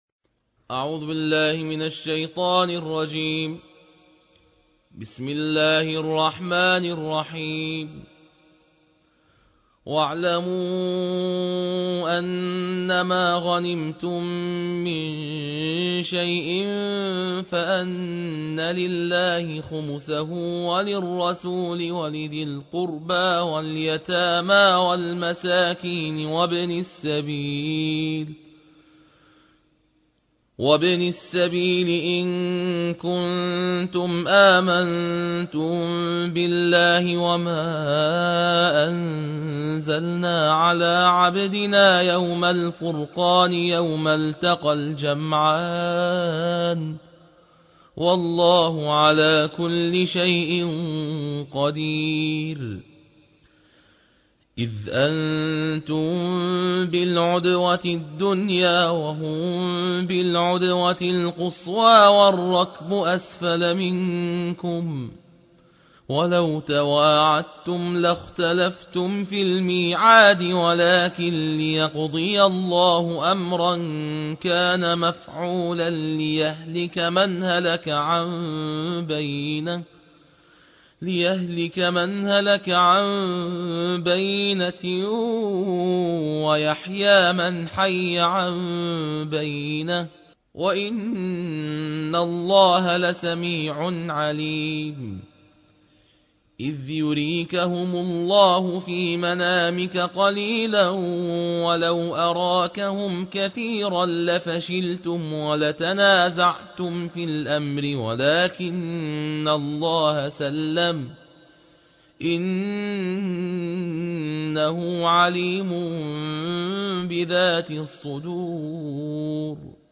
الجزء العاشر / القارئ